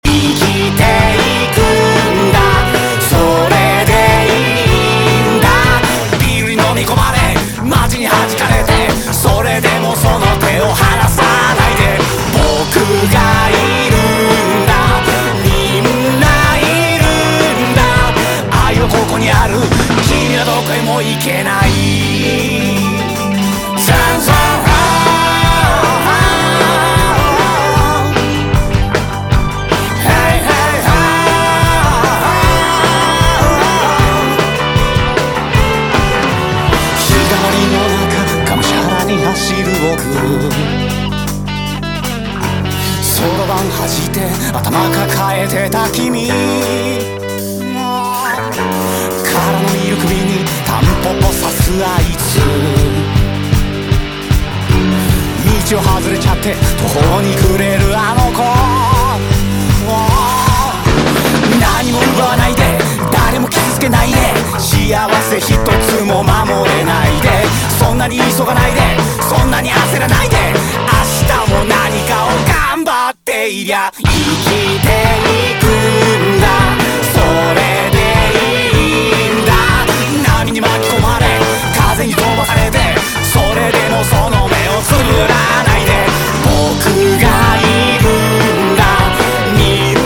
DISCO HOUSE
ストリングスが込み上げるディスコ・チューン！